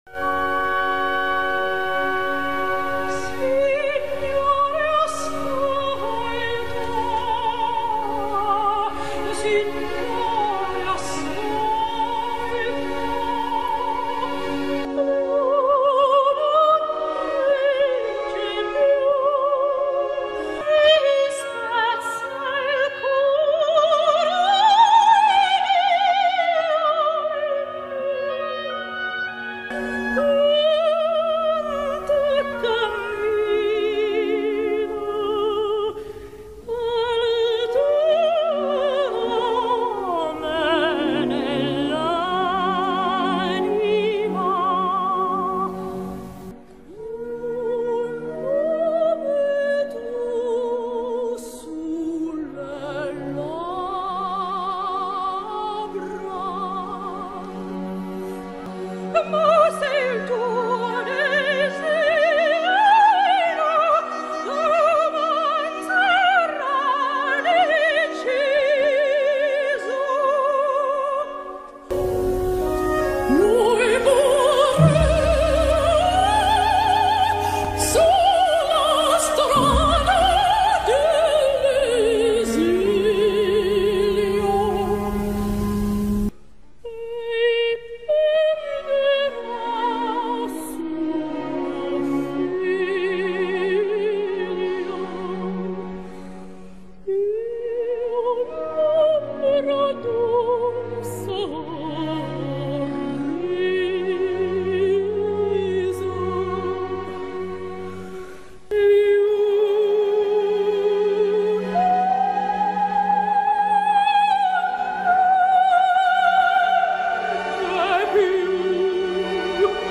vocal ID quiz